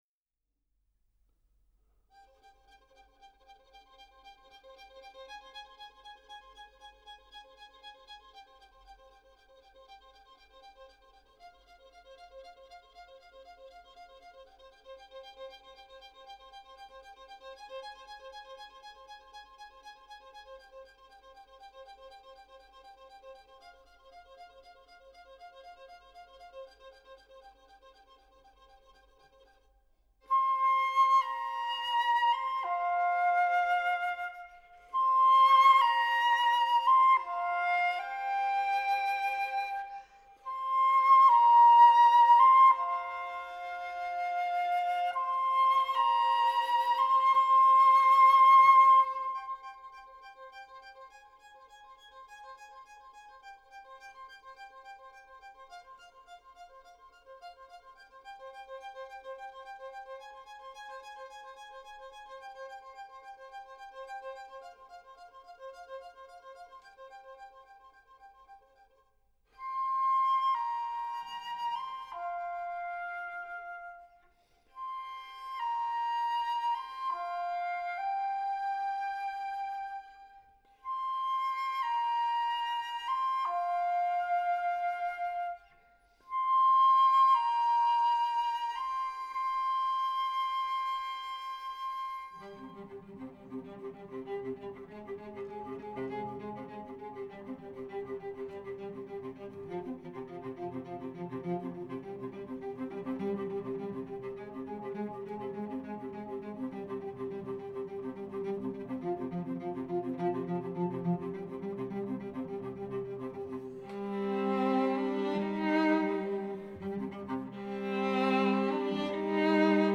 Quartet for flute, violin, viola and violoncello